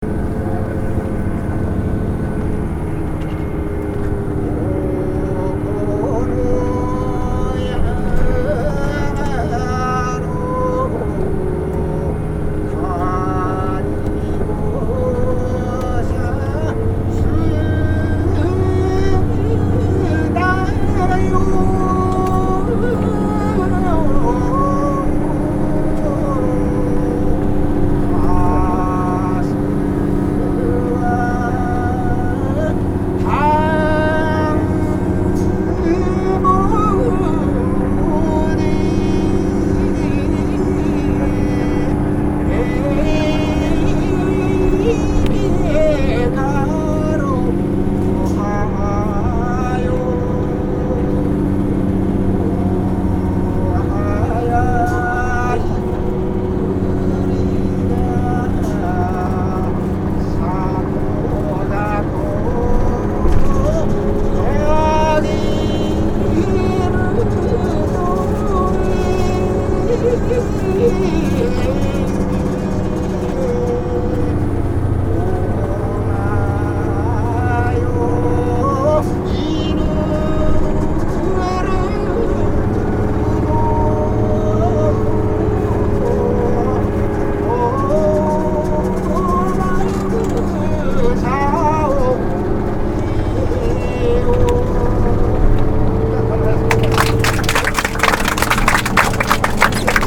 ・　旅先で得た音楽や音をアップしました。